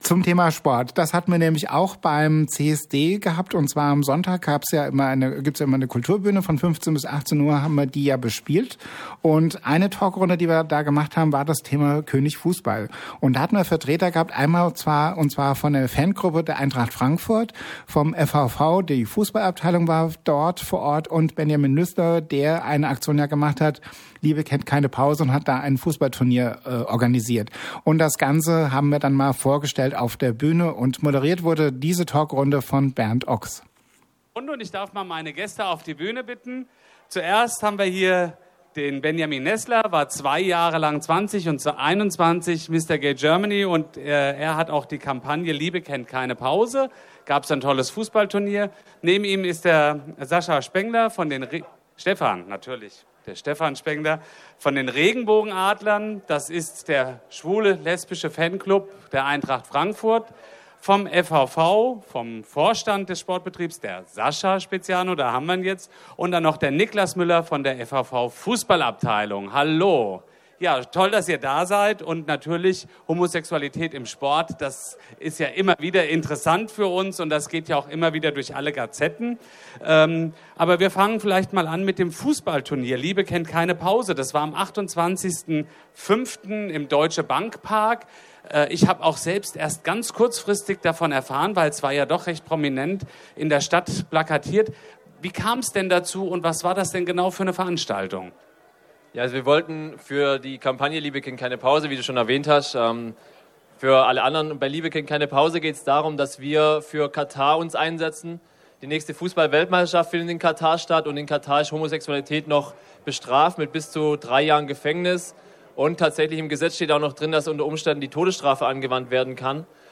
Mitschnitt einer Diskussion auf der Kulturbühne vom CSD 2022